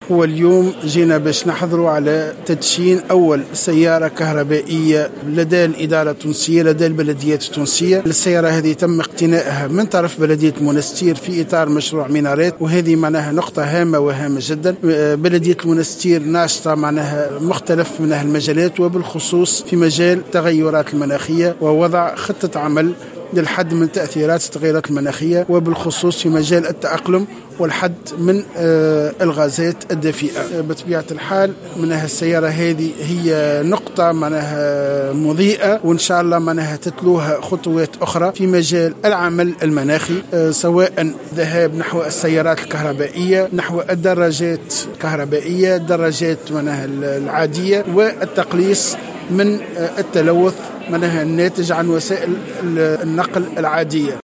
وأشاد المتحدث خلال حفل تسلييم السيارة، بحضور سفيرة السويد بتونس، بأهمية ما تقوم به بلدية المنستير في مجال العمل المناخي، للتقليص من التلوث الناتج عن وسائل النقل، مشيرا في ذات السياق الى أن البلدية ، انخرطت ضمن 12 بلدية في برامج عمل، للتخفيف من تأثيرات التغييرات المناخية.